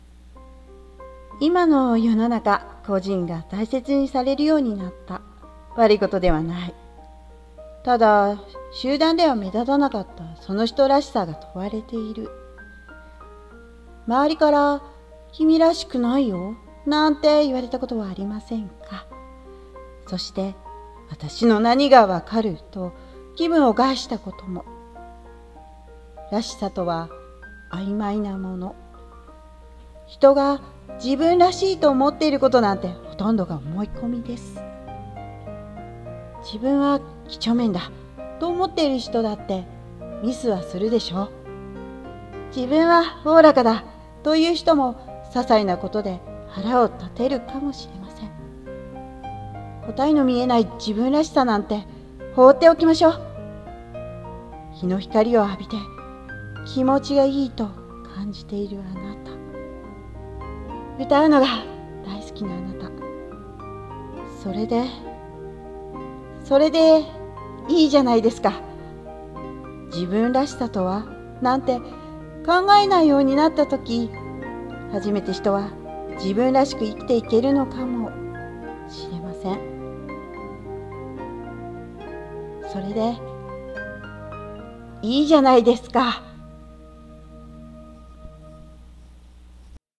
1人用声劇台本「らしさ」